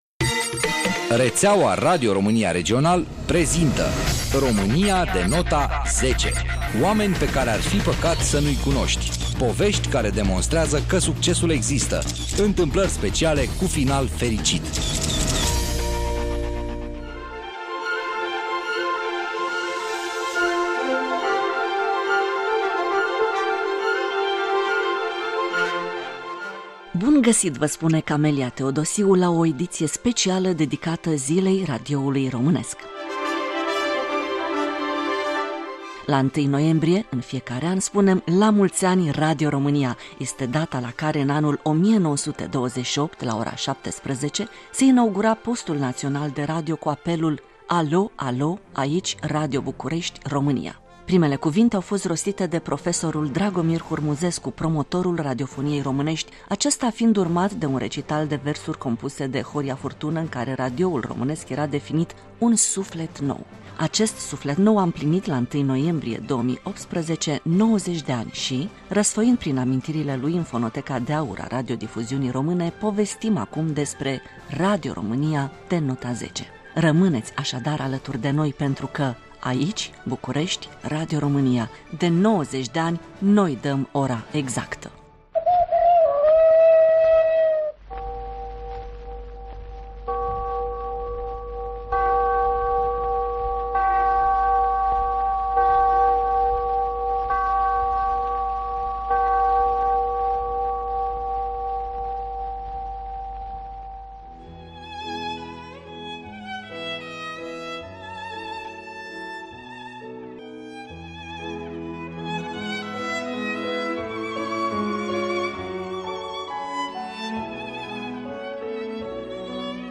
Cocoş, Clopot.